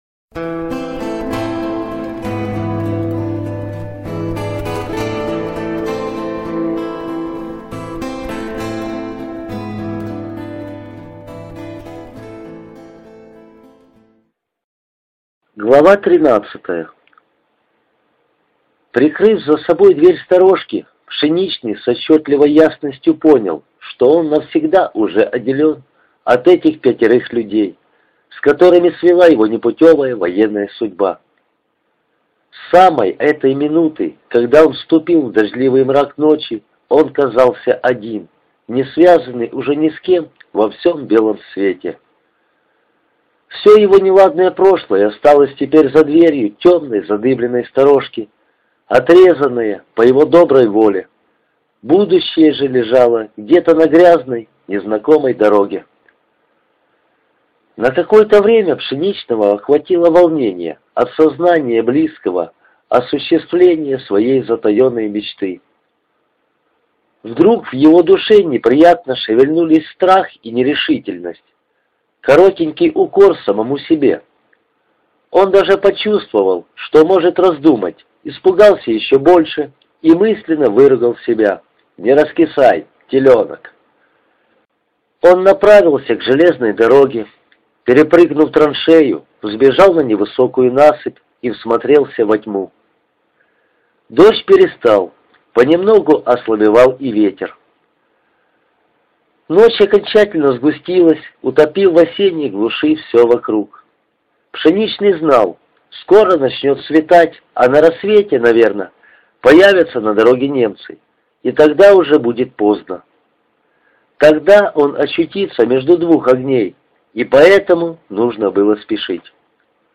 Аудиокнига Журавлиный крик
Качество озвучивания весьма высокое.